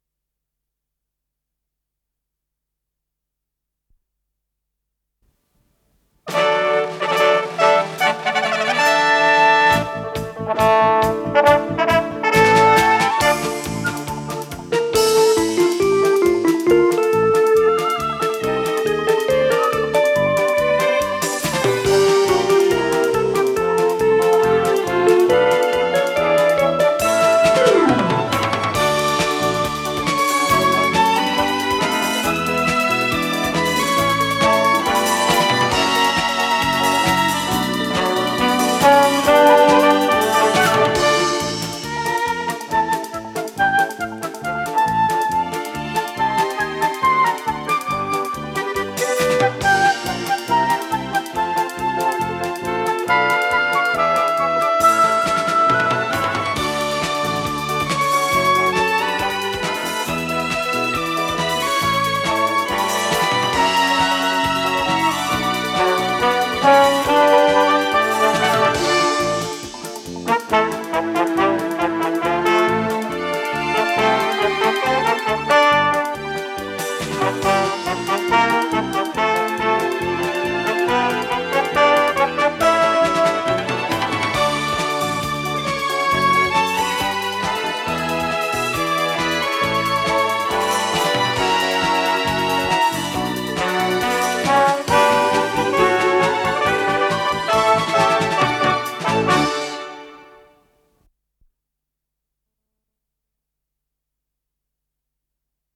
ПодзаголовокЗаставка, ре минор
ВариантДубль моно